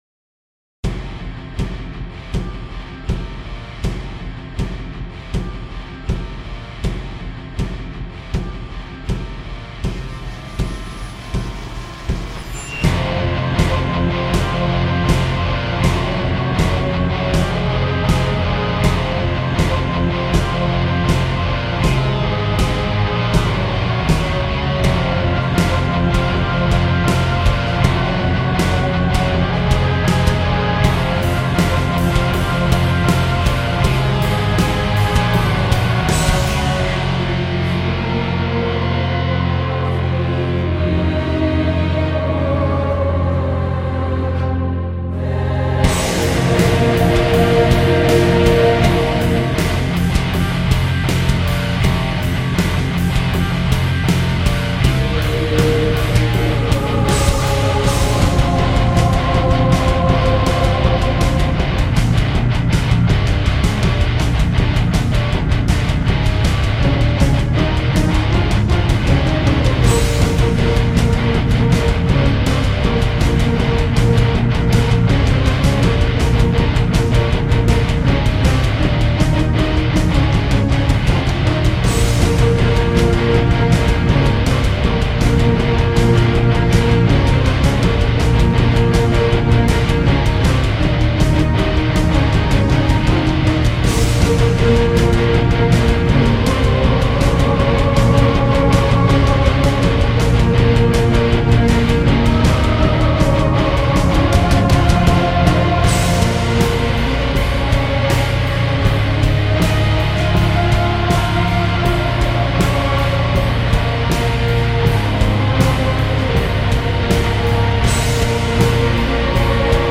really big epic sounding song
powerful and commanding
even the guitars are mostly triple tracked